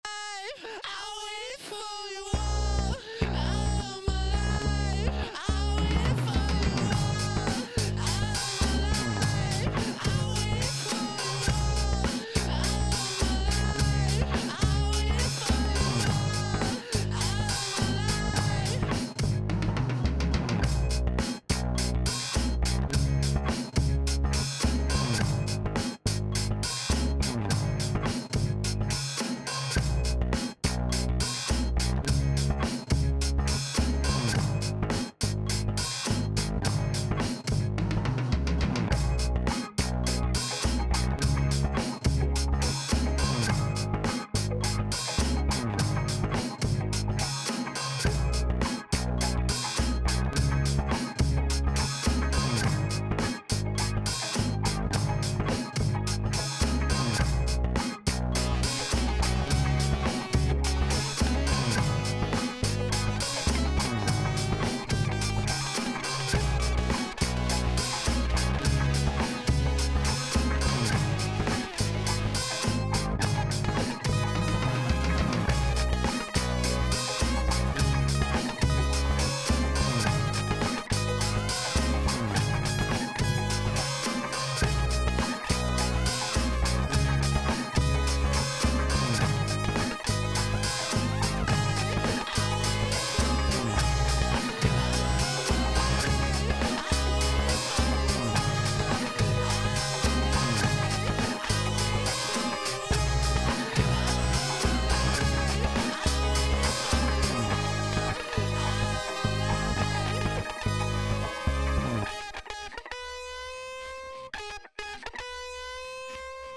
mixing advice on my neo-psychedelic art funk trip hop track?
recorded all instruments and vocals myself with sm57's during a free 3hr session at a rehearsal room, looking for any and every pointer as I'm really trying to hone down the mix.
things I'm specifically looking to fix: lot of bleed especially in the drum track, you can hear the metronome.
another drum question: put a very slight bit of gated delay+reverb, should I keep those effects mono like the original drum track or widened? right now it's at around 50% separation for the wet and still 100% mono on the dry
and generally want to de mud the mix, put low pass and high pass filters where I thought it was appropriate but still feels muddy.